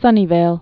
(sŭnē-vāl)